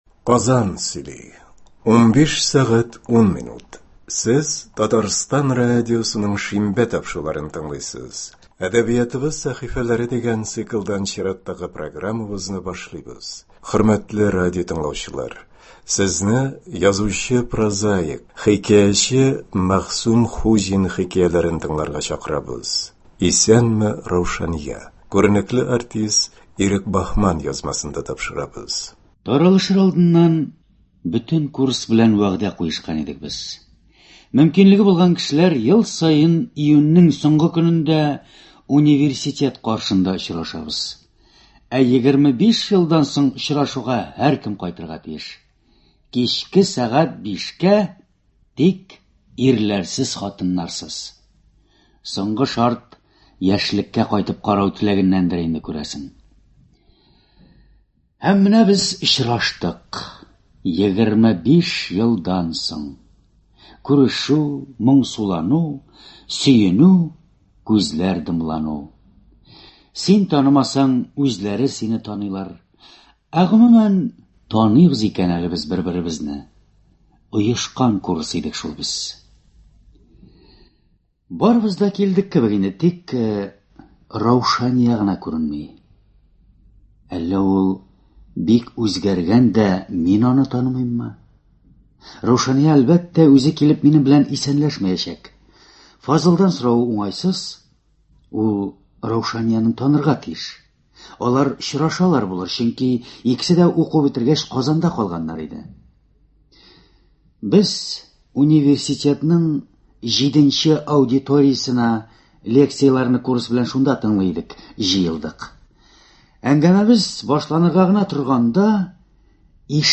нәфис сүз остасы